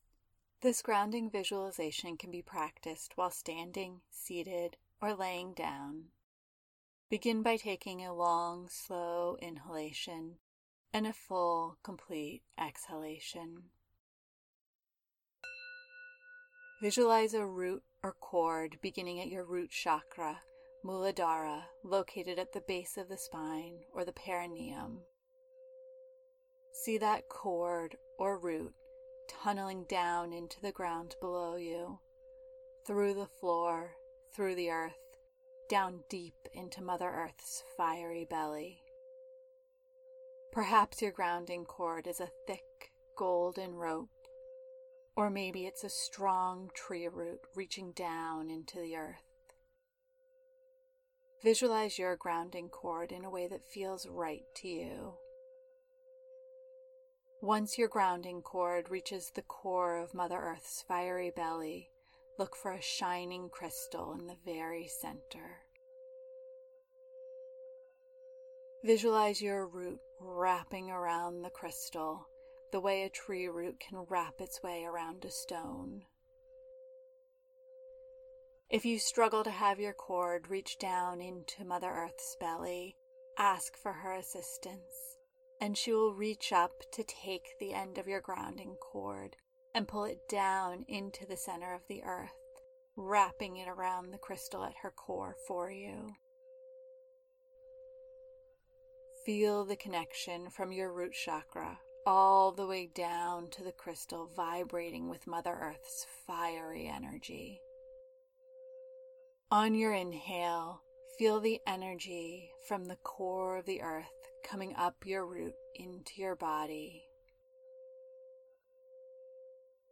If you are new to the practice of grounding,  this audio will help you to understand the process through a guided visualization.